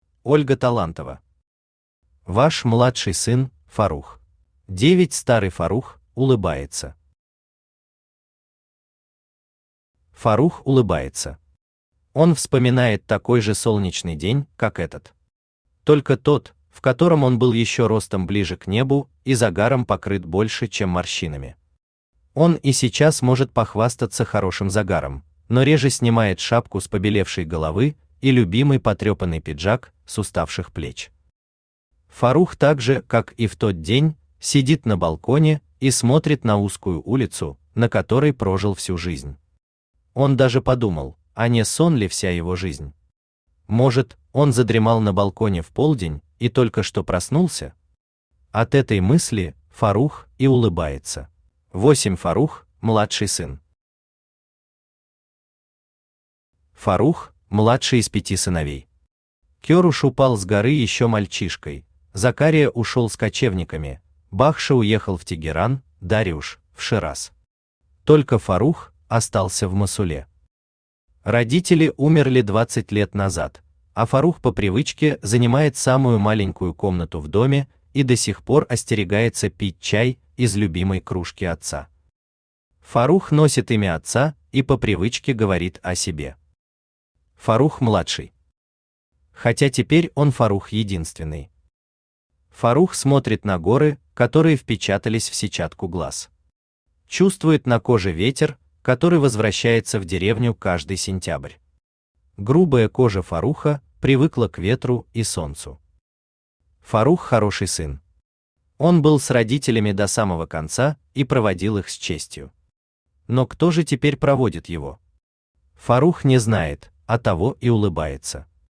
Аудиокнига Ваш младший сын Фаррух | Библиотека аудиокниг
Aудиокнига Ваш младший сын Фаррух Автор Ольга Талантова Читает аудиокнигу Авточтец ЛитРес.